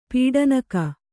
♪ pīḍanaka